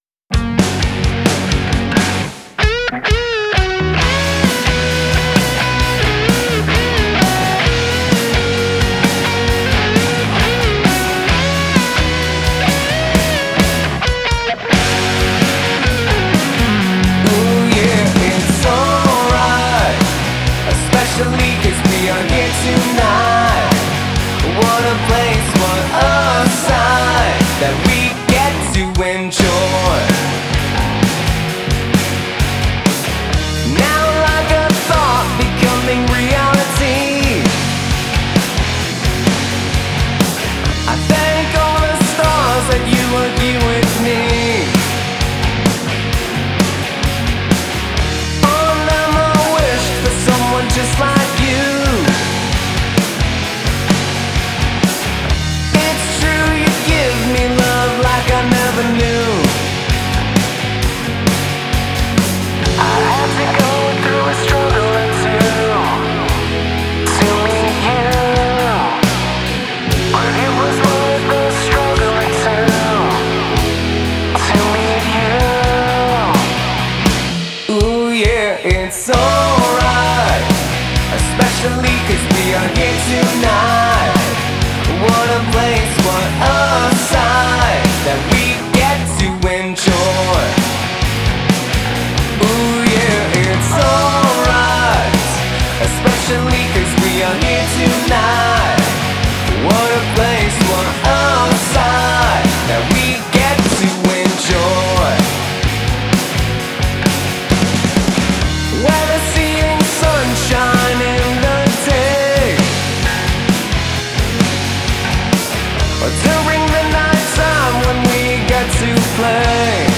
Upbeat, sunny